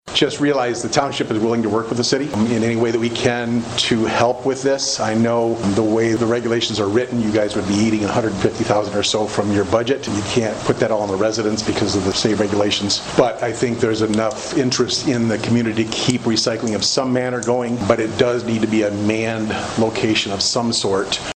(STURGIS) – The topic of keeping recycling in Sturgis was addressed again during the August 13 City Commission meeting.
Sturgis Township Supervisor Von Metzger says the Township would be willing to work with the City to keep recycling available.